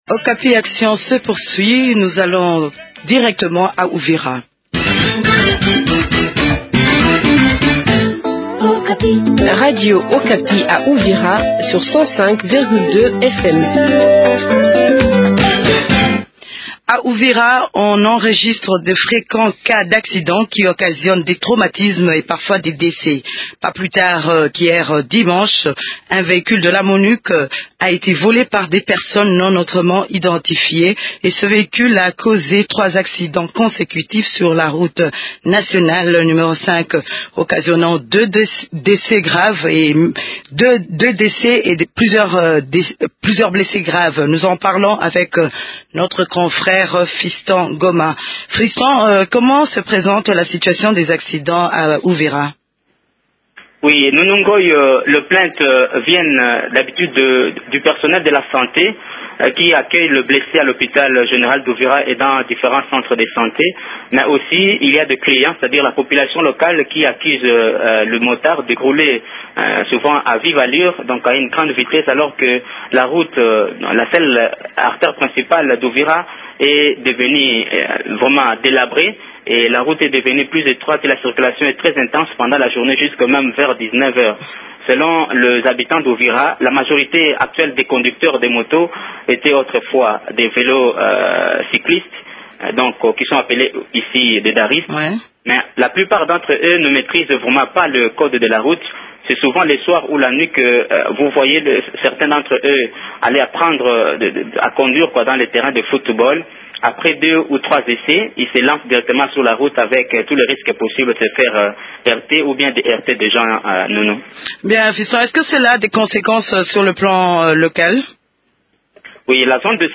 reçoivent M. Daniel ELOKO, administrateur du territoire d’Uvira.